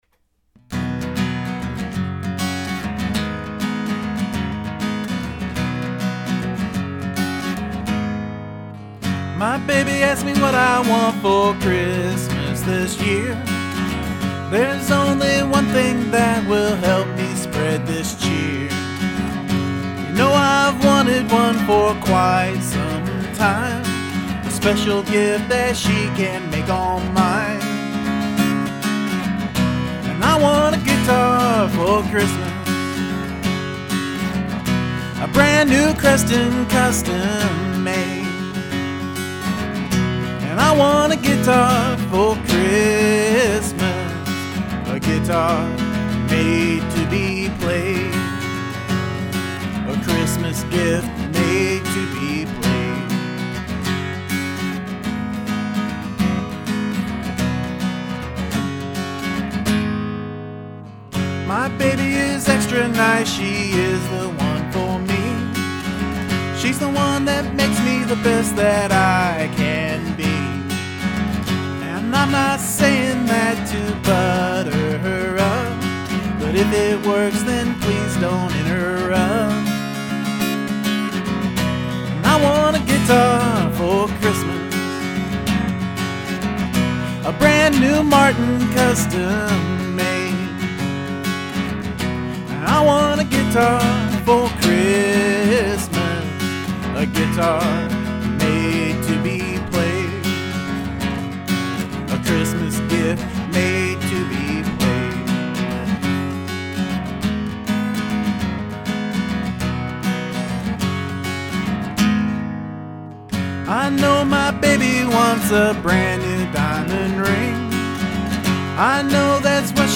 First recorded take, live in the bedroom. No overdubs.
Yamaha acoustic/electric DI
AT2020 for Vox (and acoustic bleed)
Bounced to a stereo pair and converted to WAV via the br wav converter program
The acoustic and vox sound great.
and well played and sung